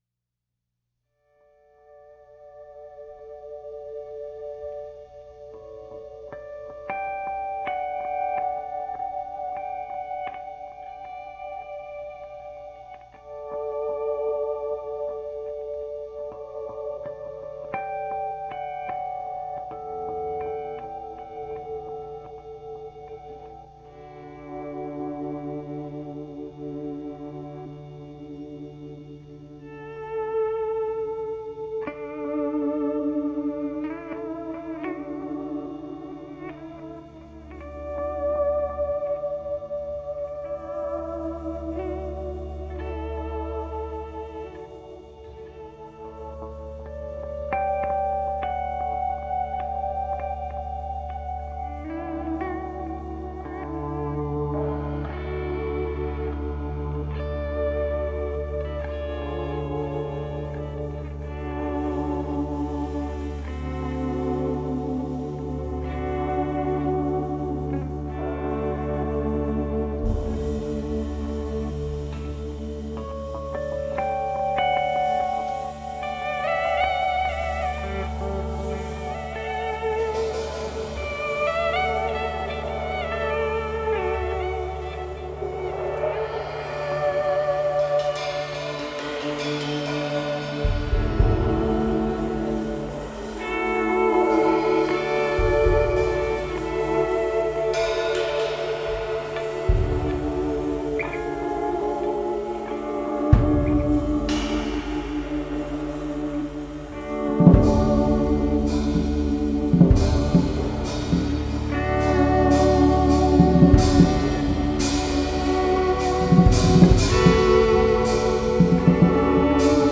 early morning improv set played live
bass
drums
guitar
Jazz Rock